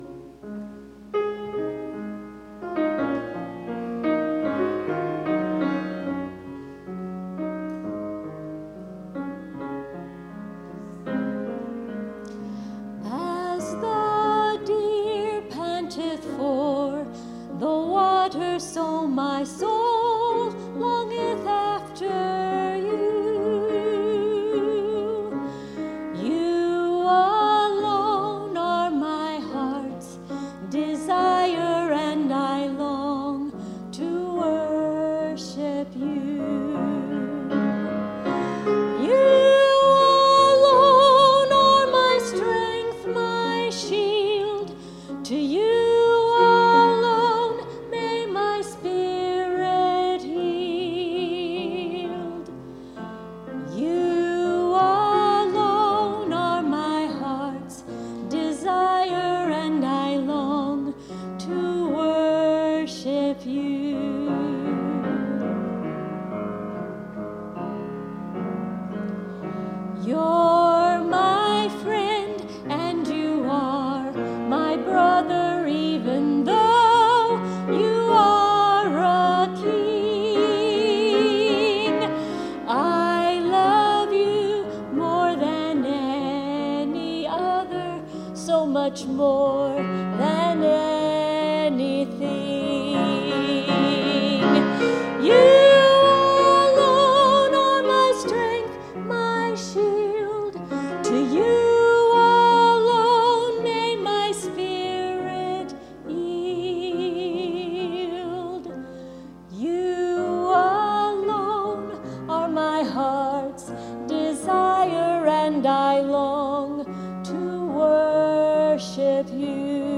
As The Deer (Solo)